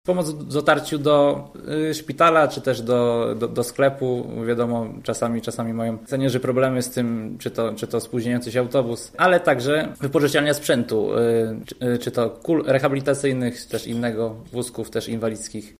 Założenia programu „Złota Rączka dla seniora” przedstawili podczas dzisiejszej konferencji Młodzi Demokraci, a jednocześnie kandydaci do rady miasta z list Koalicji Obywatelskiej.